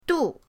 du4.mp3